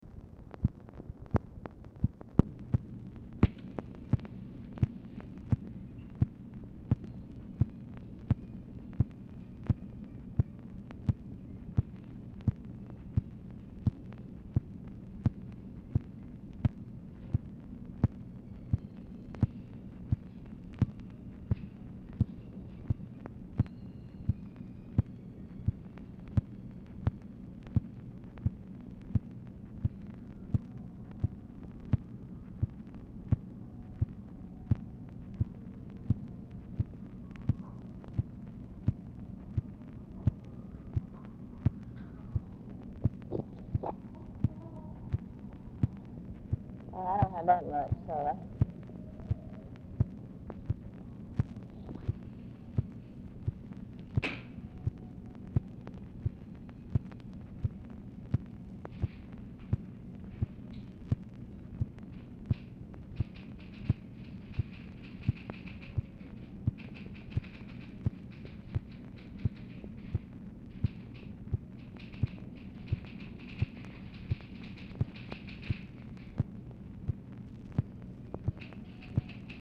PRIMARILY OFFICE NOISE, INAUDIBLE OFFICE CONVERSATION
Format Dictation belt
Oval Office or unknown location